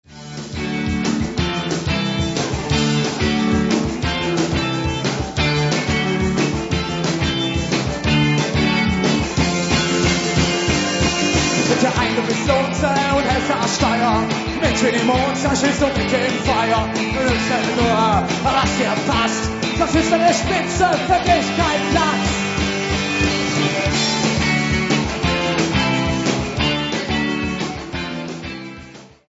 Neuen Deutschen Welle